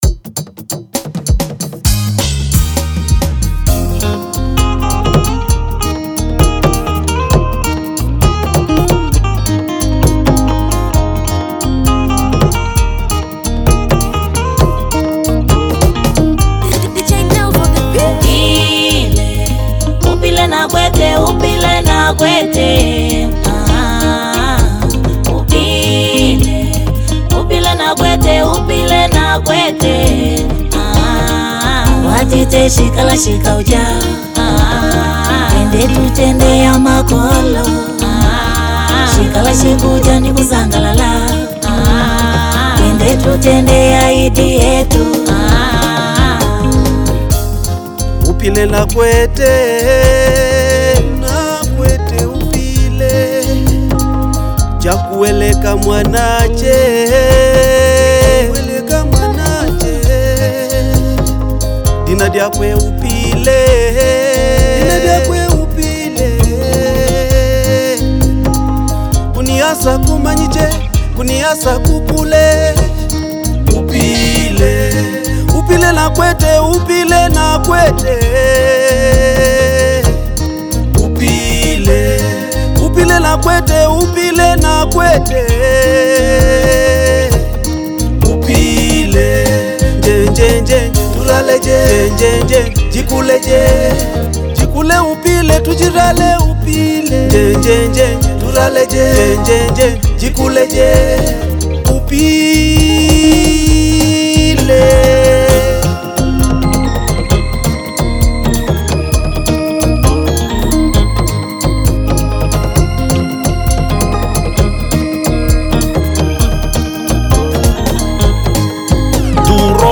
Na guitarra